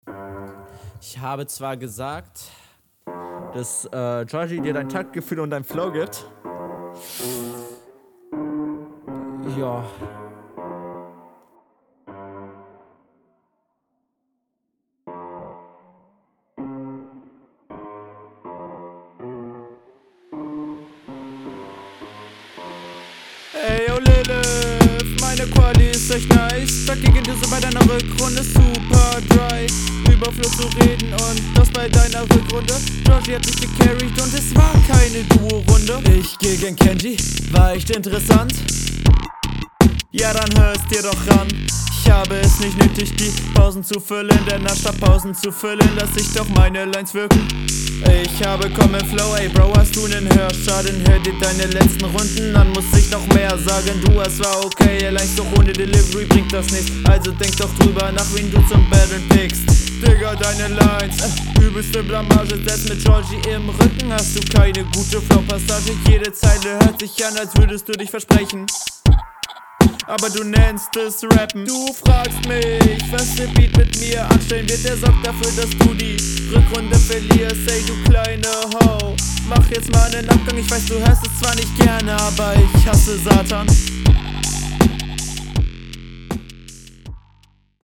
der flow ist hier, so wie bei deinem gegner teilweise holprig und recht unsafe der …